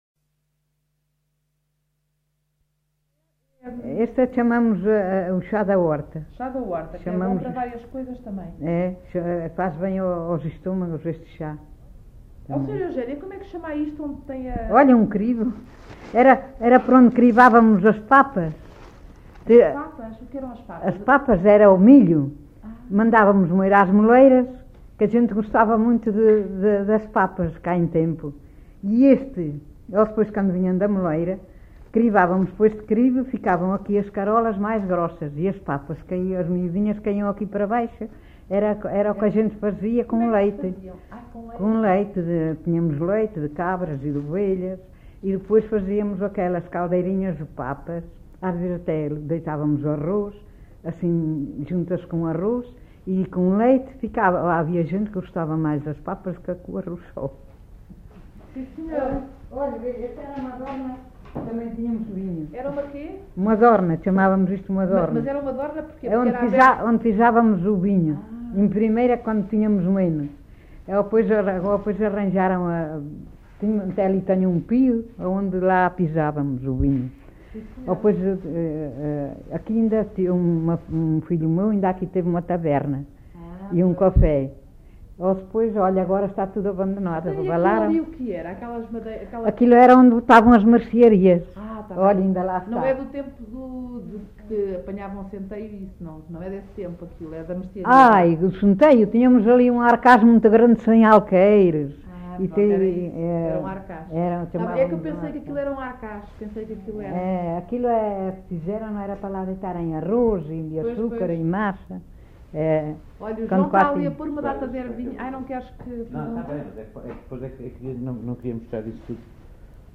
LocalidadeAssanhas (Celorico da Beira, Guarda)